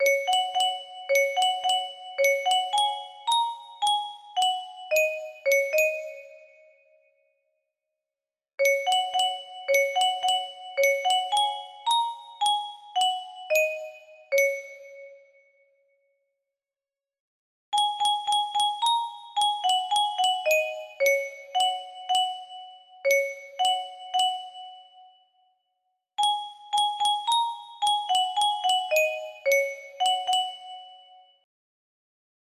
Hanging Stars music box melody
This is a simple layout of the tune to my W.I.P song, Hanging Stars.